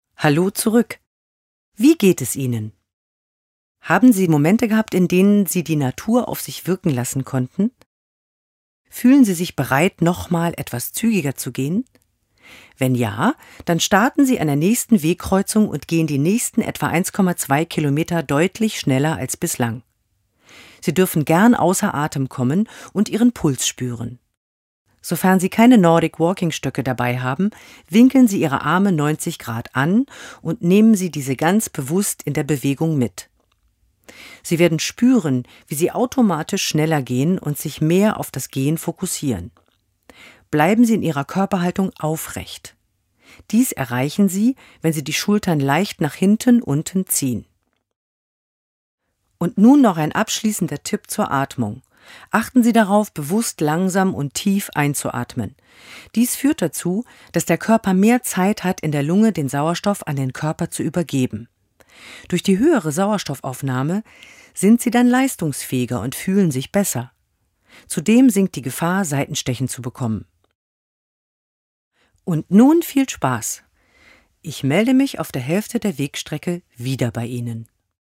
Audioguide
Sprecherin für die deutsche Audiotour